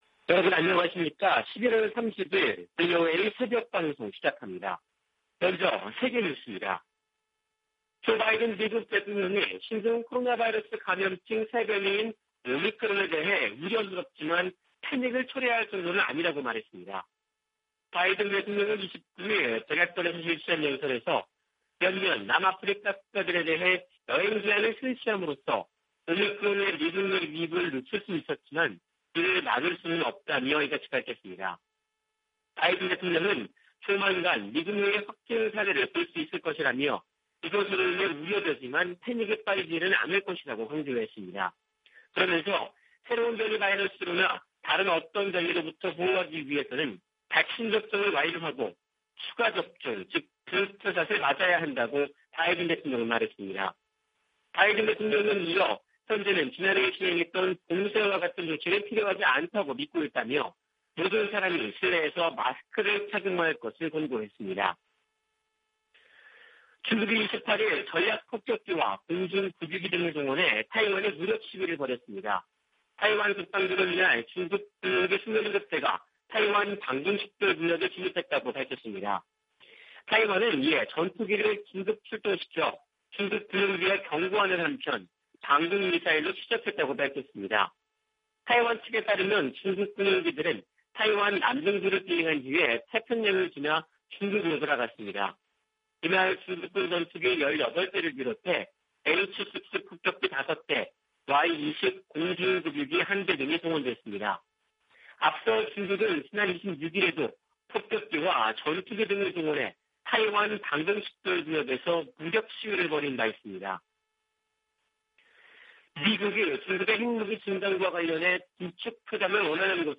세계 뉴스와 함께 미국의 모든 것을 소개하는 '생방송 여기는 워싱턴입니다', 2021년 11월 30일 아침 방송입니다. '지구촌 오늘'에서는 신종 코로나바이러스 '오미크론' 변이 확산으로 많은 나라가 남아프리카에서 들어오는 항공편을 봉쇄한 소식, '아메리카 나우'에서는 미국 정부가 연방 부지에서 석유와 천연가스를 시추하는 기업에 부담하는 비용을 인상할 방침이란 소식 전해드립니다.